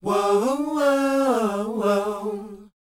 WHOA G#C.wav